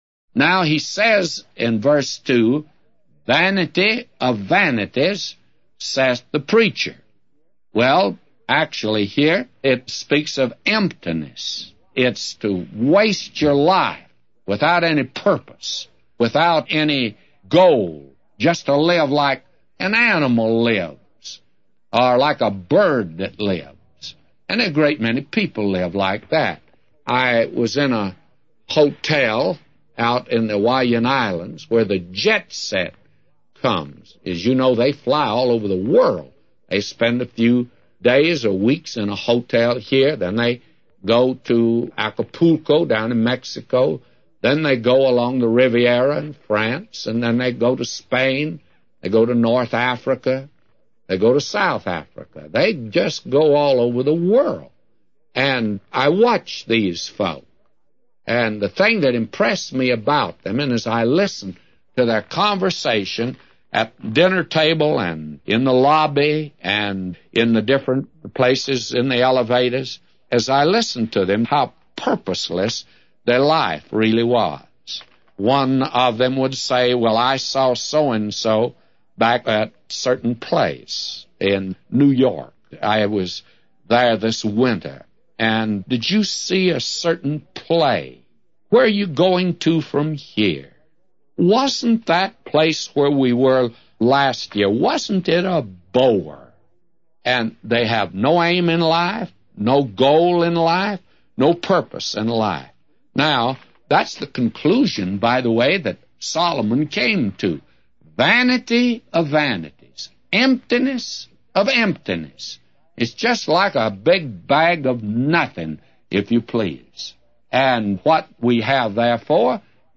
A Commentary By J Vernon MCgee For Ecclesiastes 1:2-999